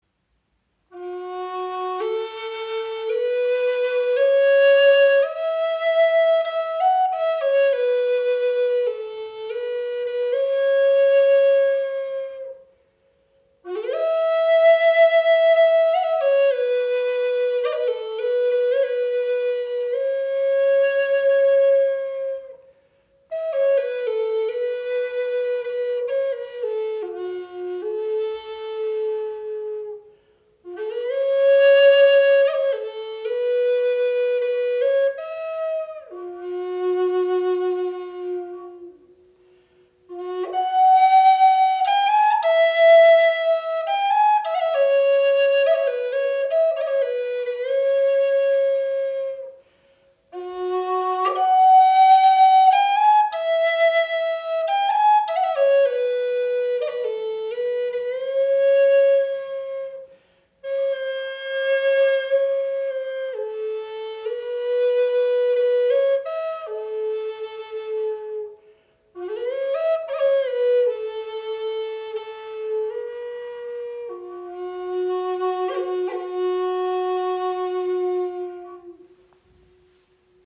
Gebetsflöte in F# - 432 Hz
• Icon Bass Gebetsflöte in tiefem D mit 432 Hz
• Icon 52 cm lang, 6 Grifflöcher
Sie schenkt Dir ein wundervolles Fibrato, kann als Soloinstrument gespielt werden oder als weiche Untermahlung Deiner Musik.